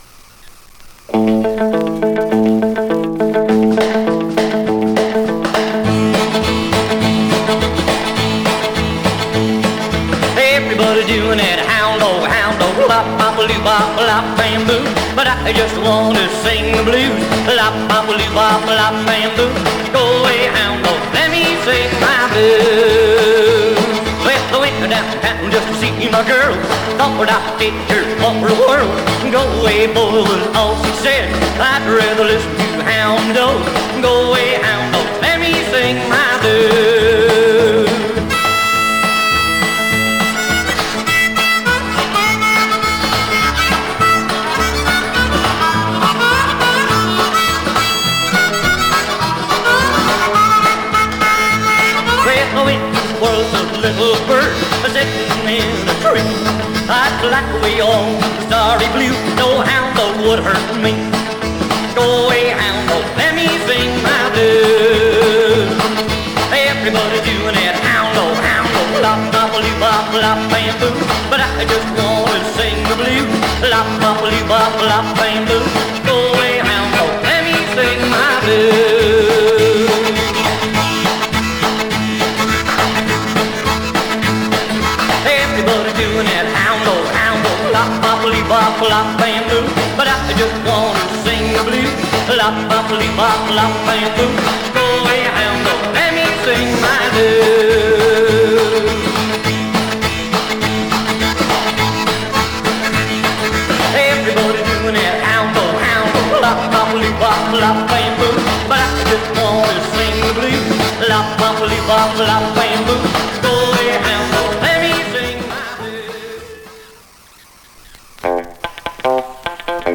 US 50's Rockabilly / R&R
オリジナル盤7インチ